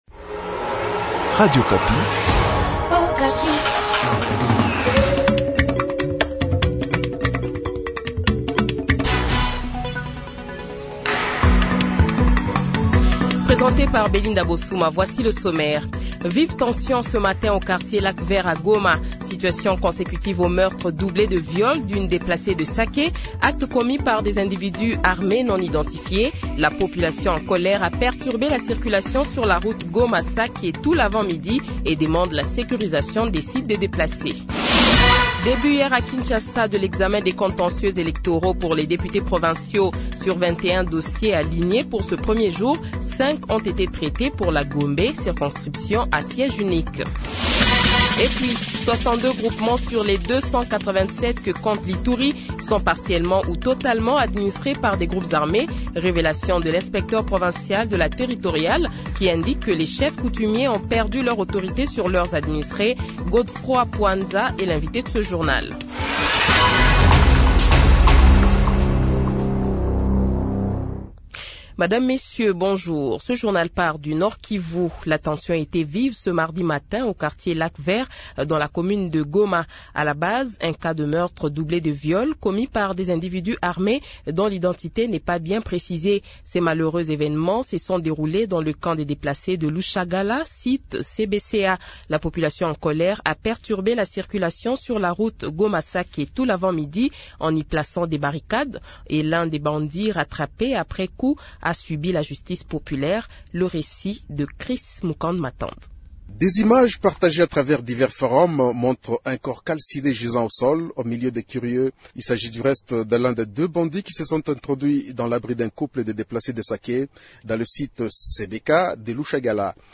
Journal Francais Midi